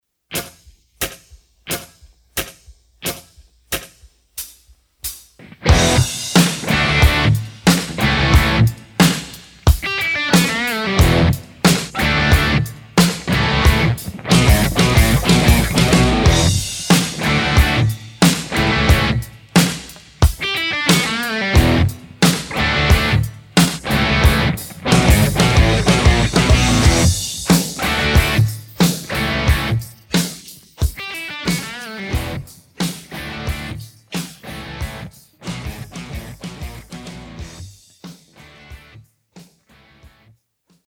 Drums und Bass habe ich mit Hilfe von Spectrallayers isoliert. Gitarre ist der 1987x (mit Fryette) in eine Mesa Boogie Roadster 2x12 mit V30 und mit einem SM57 abgenommen.